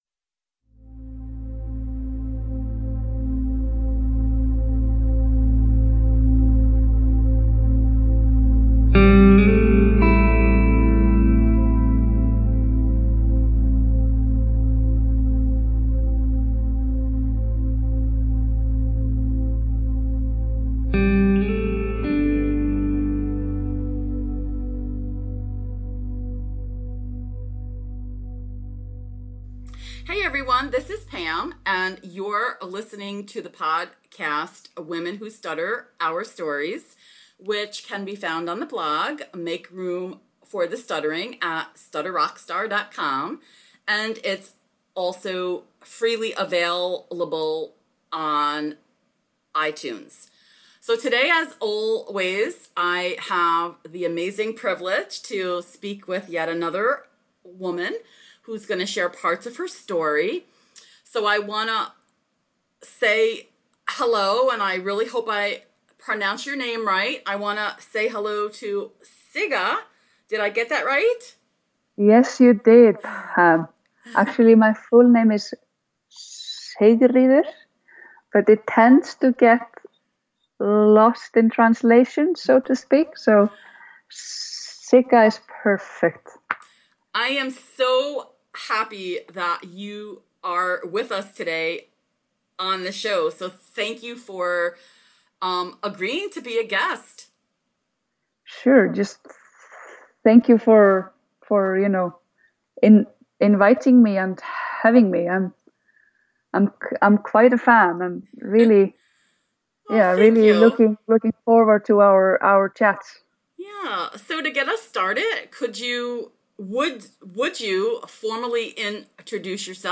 This conversation was one of those where we had no clue we would wind up doing such a deep dive. We talked about self advocacy, unintentional authenticity, reacting to other people’s reactions to our speech and the energy drain we who stutter face when we are constantly thinking and listening to our inner head chatter.